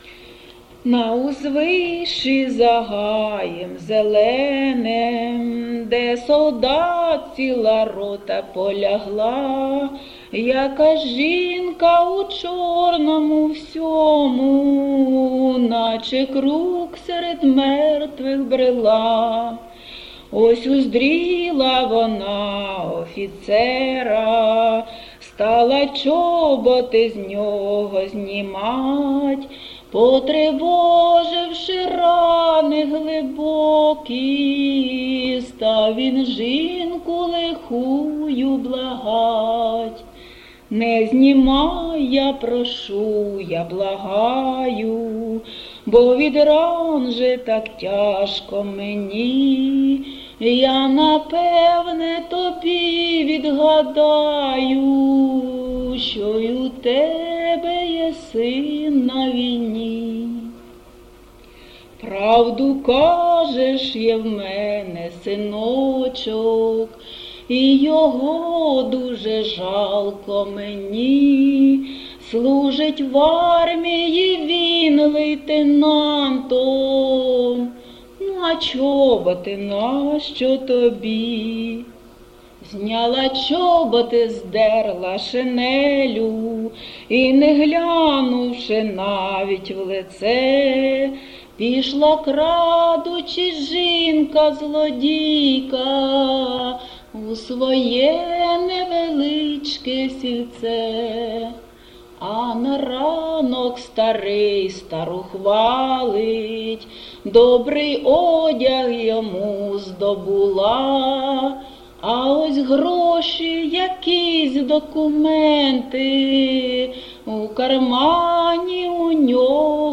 Місце записум. Костянтинівка, Краматорський район, Донецька обл., Україна, Слобожанщина